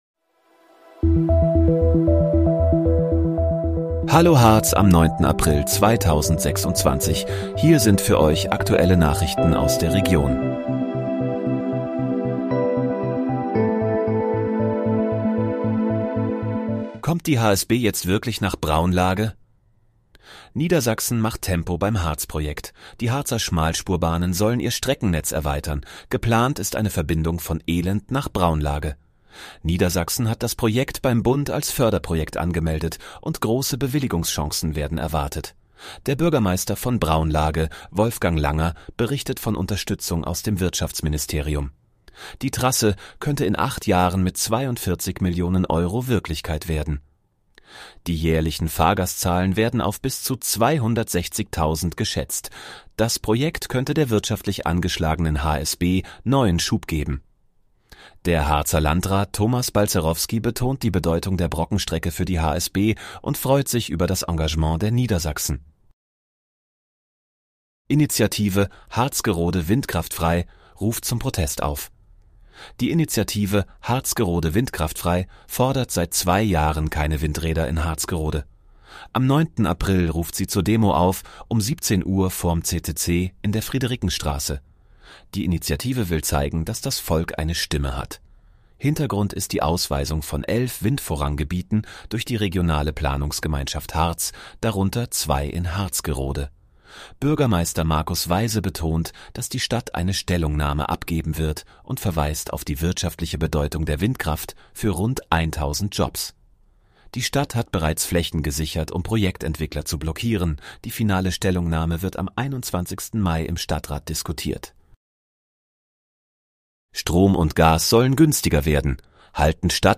Hallo, Harz: Aktuelle Nachrichten vom 09.04.2026, erstellt mit KI-Unterstützung